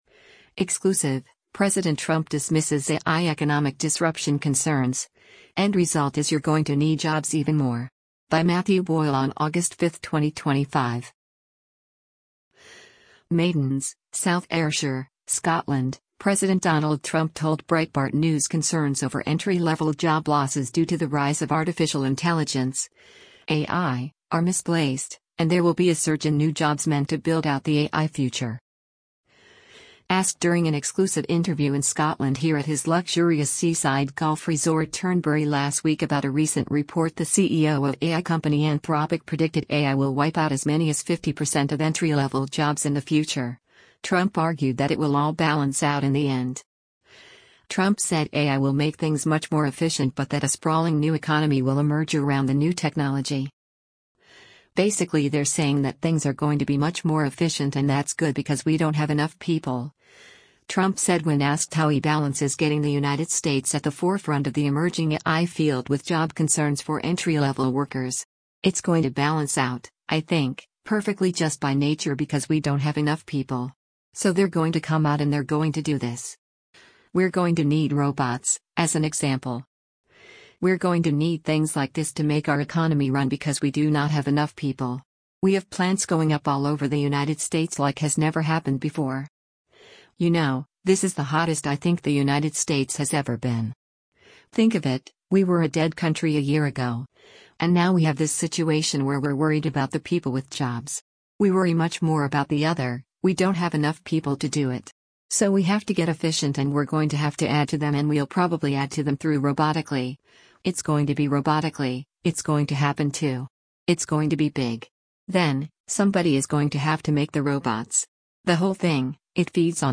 Asked during an exclusive interview in Scotland here at his luxurious seaside golf resort Turnberry last week about a recent report the CEO of AI company Anthropic predicted AI will wipe out as many as 50 percent of entry-level jobs in the future, Trump argued that it will all “balance out” in the end.